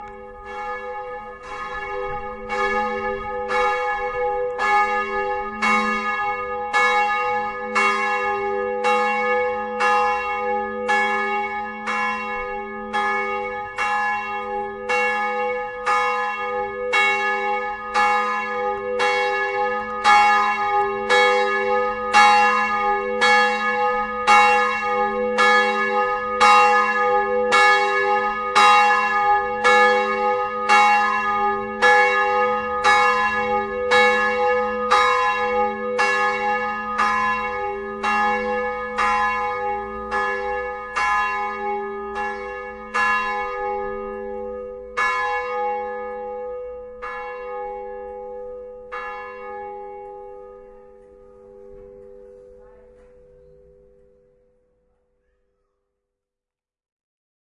现场录音 " 奥赫里德的教堂 2
描述：马其顿奥赫里德的教堂钟声
Tag: 马其顿 现场记录 奥赫里德 churchbells